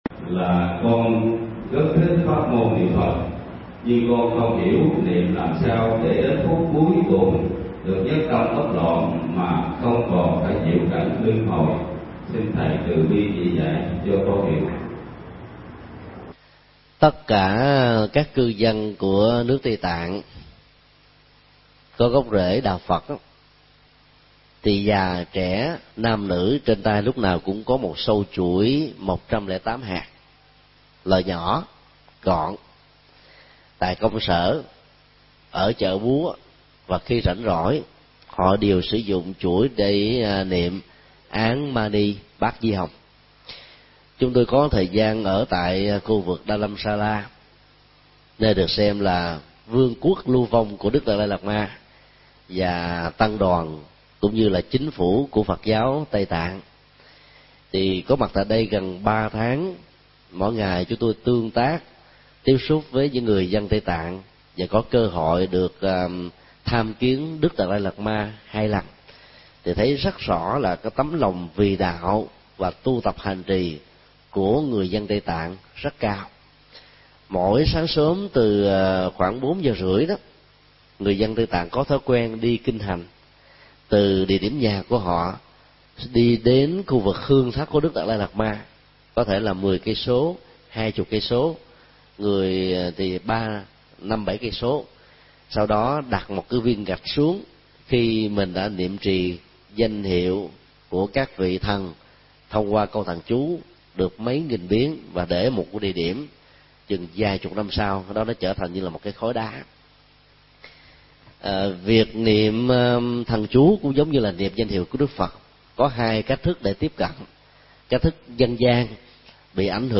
Vấn đáp: Phương pháp niệm Phật nhiếp tâm